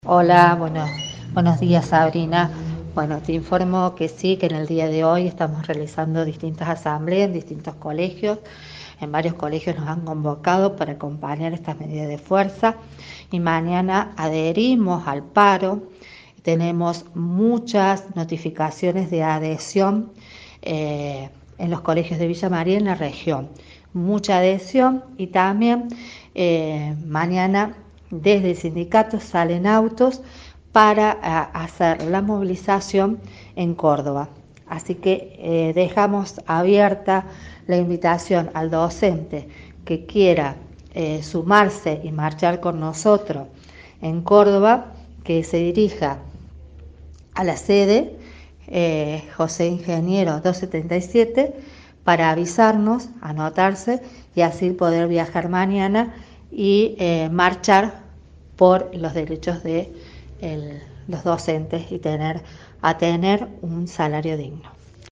dialogamos